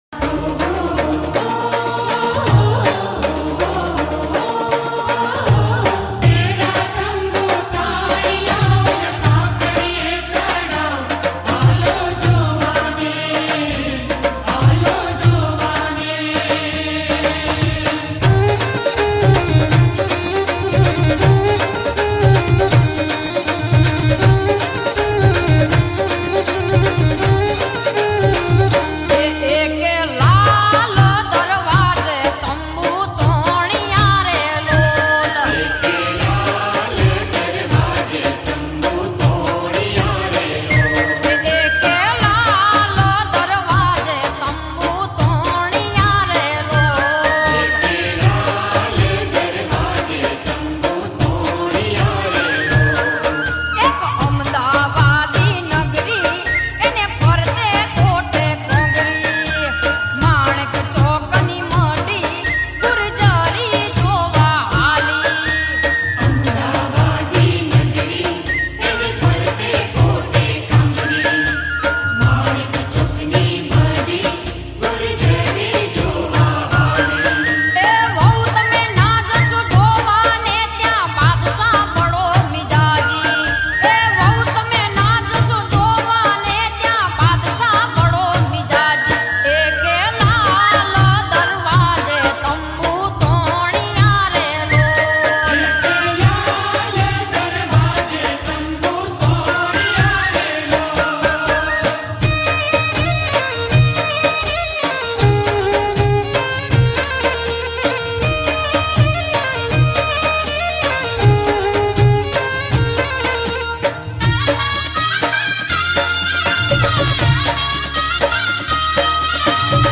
ગીત સંગીત ગરબા - Garba